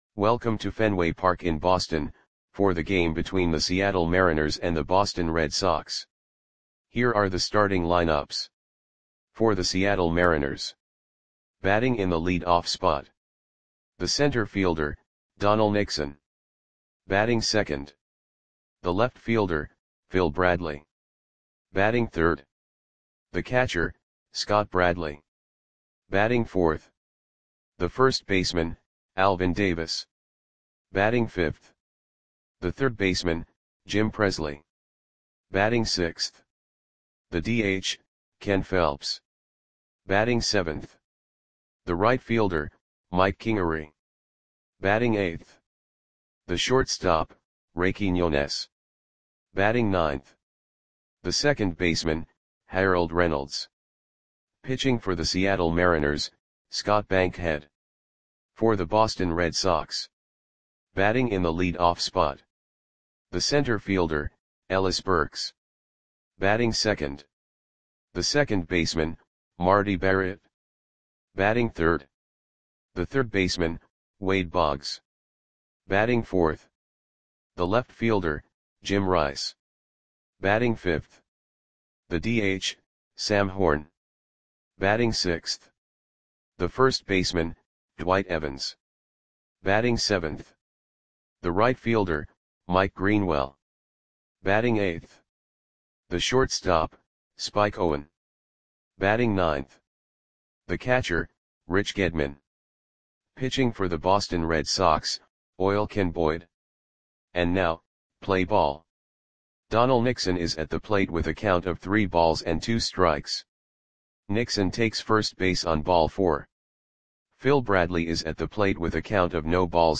Audio Play-by-Play for Boston Red Sox on July 25, 1987
Click the button below to listen to the audio play-by-play.